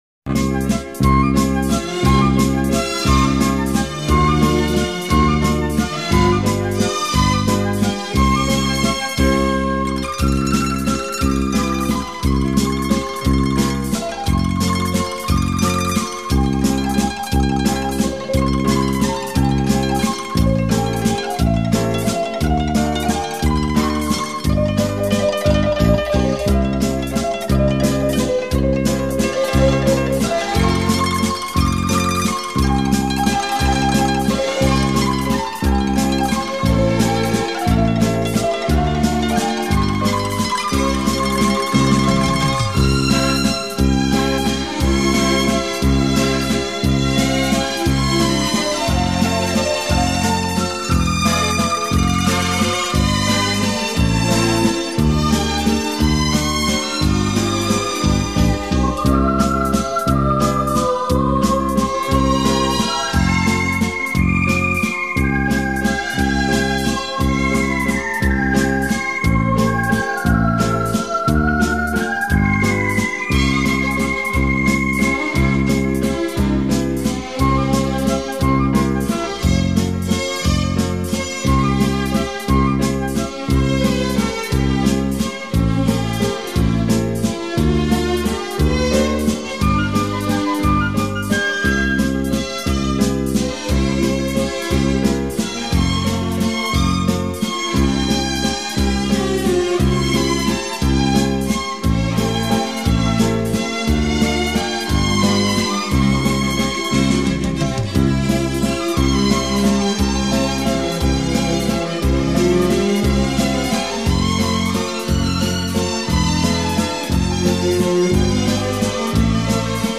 流行音乐交谊舞曲